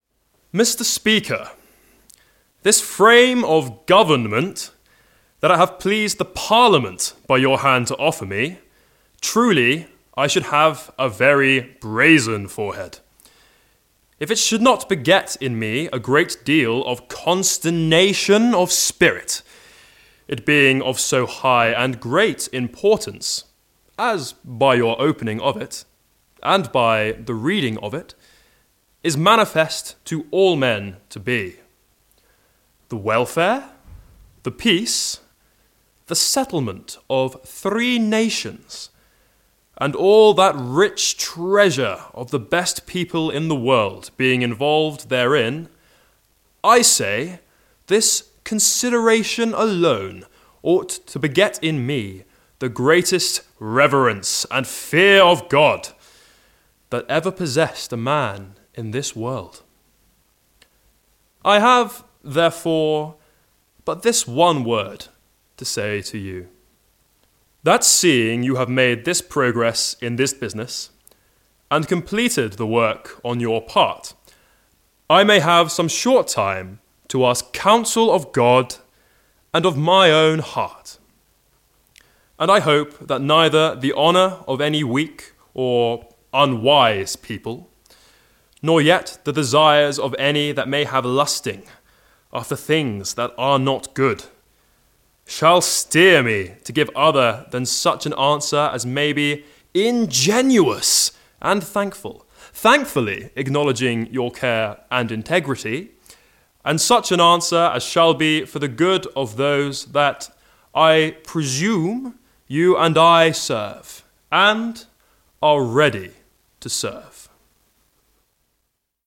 Speech 11 to the Second Protectorate Parliament on 31st March 1657 on the offer of the Title of King.